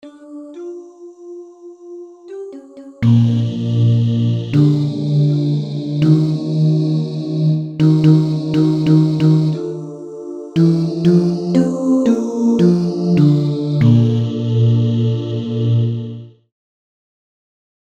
synth voices & a piano